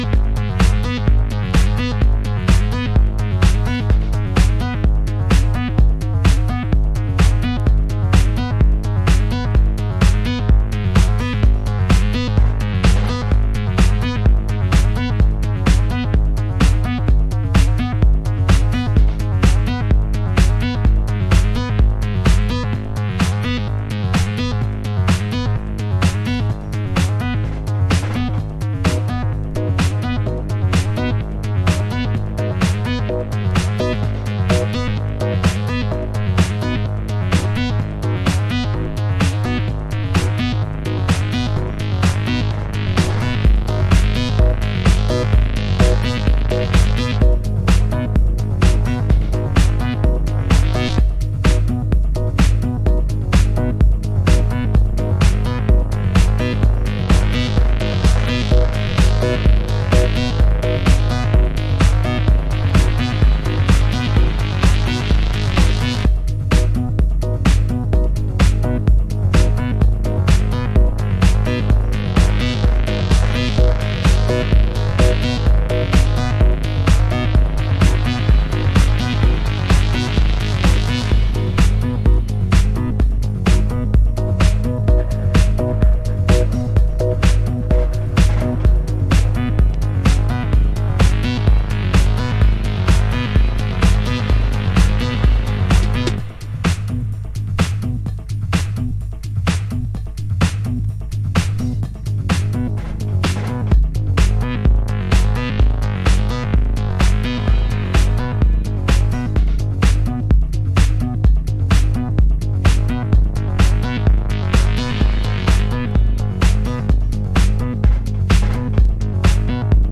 House / Techno
グルーヴィーなアシッドテクノ